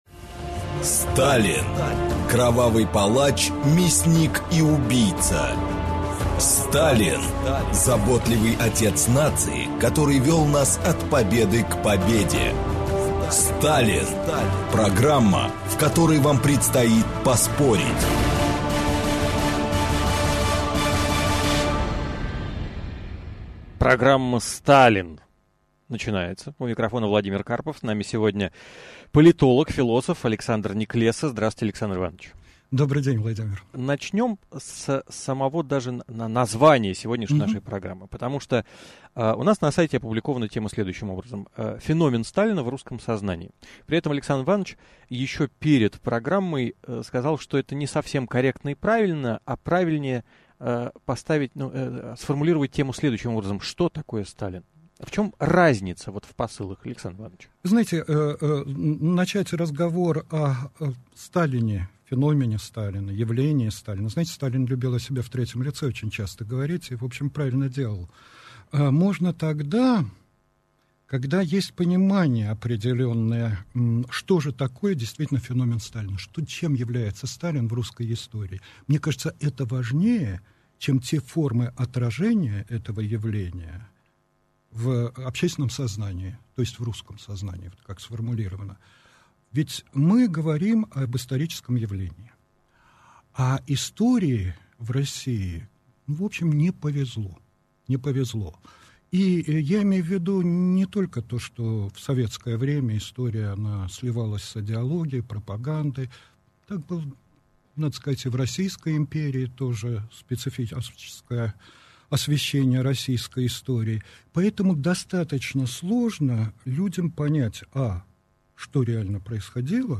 Аудиокнига Феномен Сталина в русском сознании. Часть 1 | Библиотека аудиокниг